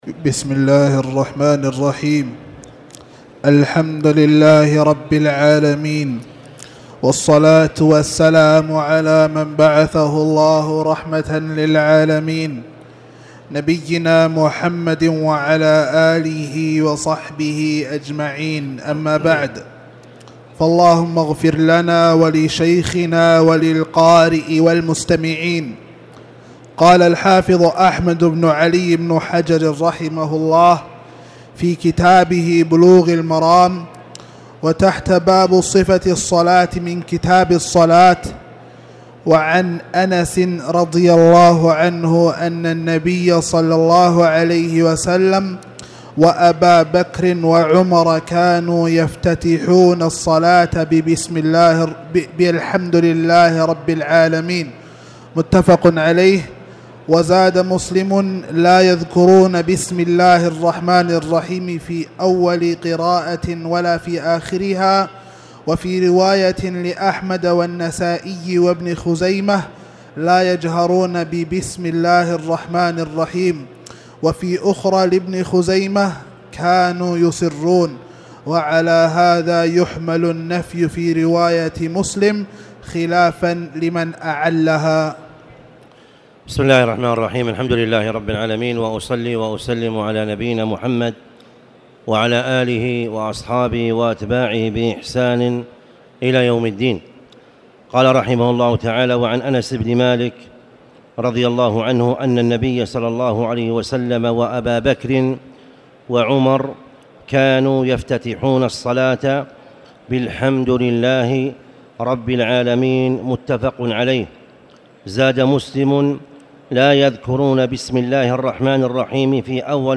تاريخ النشر ٢٩ رجب ١٤٣٨ هـ المكان: المسجد الحرام الشيخ